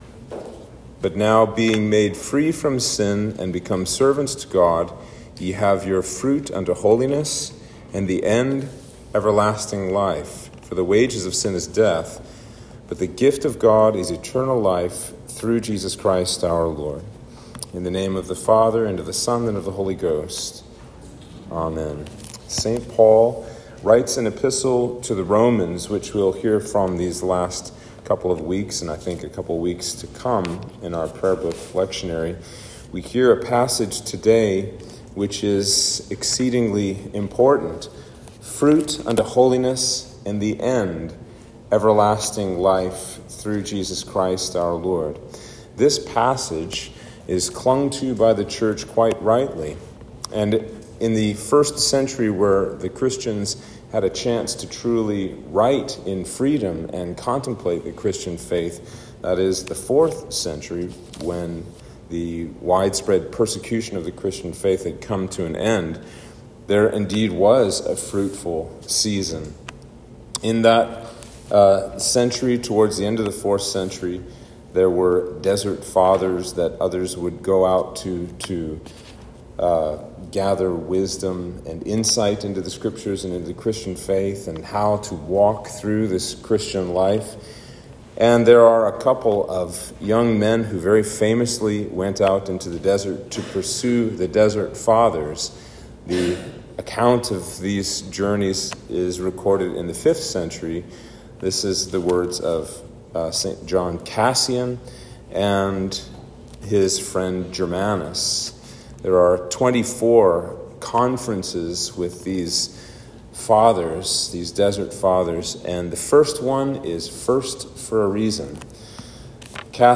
Sermon for Trinity 7